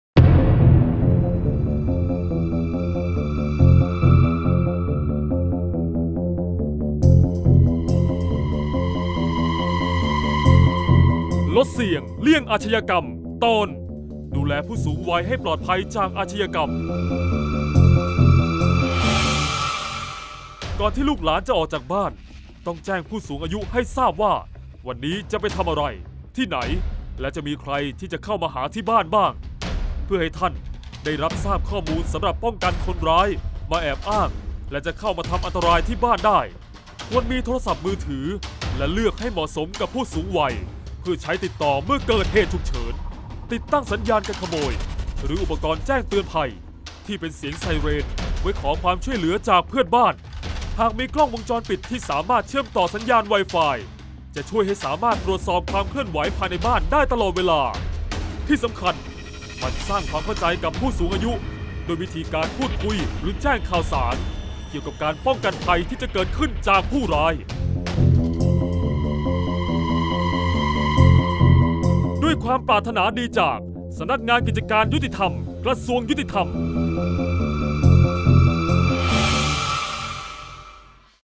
เสียงบรรยาย ลดเสี่ยงเลี่ยงอาชญากรรม 18-ป้องกันผู้สูงวัยจากอาชญากรรม